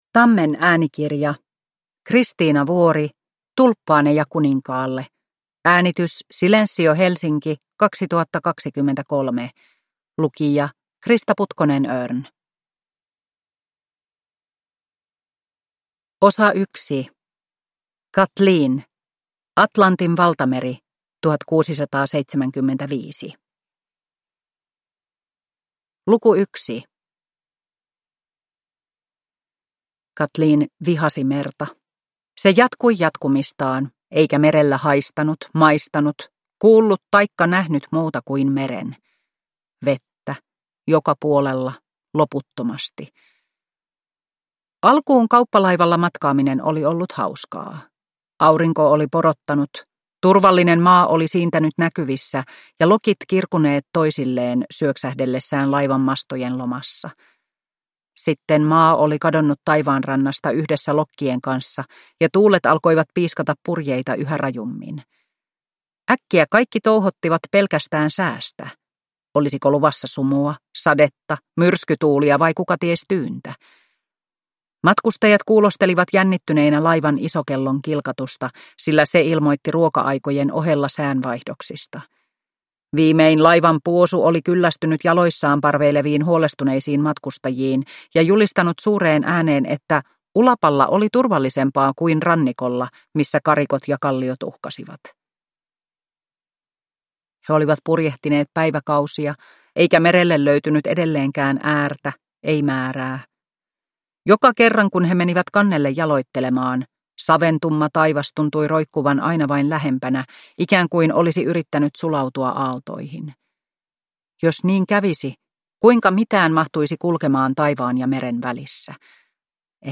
Tulppaaneja kuninkaalle – Ljudbok – Laddas ner